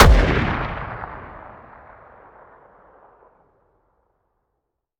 weap_br1_fire_plr_atmo_ext1_06.ogg